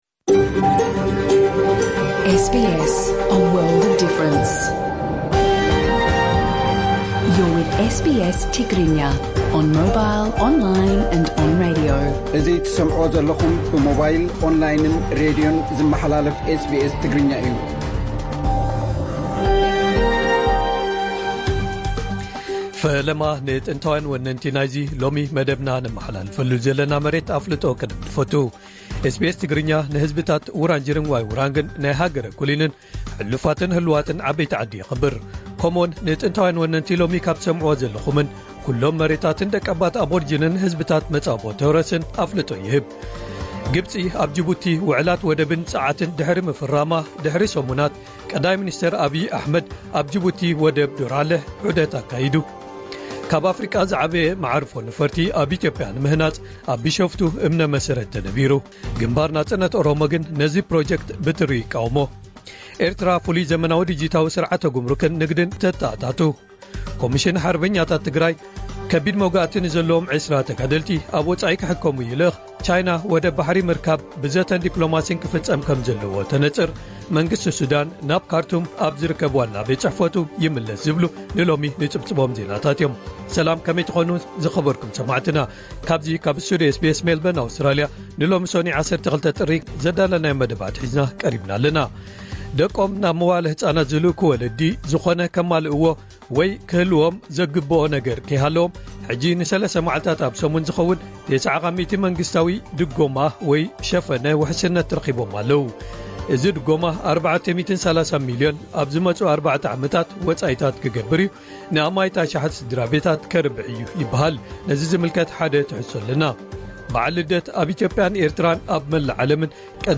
ቀጥታ ምሉእ ትሕዝቶ ኤስ ቢ ኤስ ትግርኛ (12 ጥሪ 2026)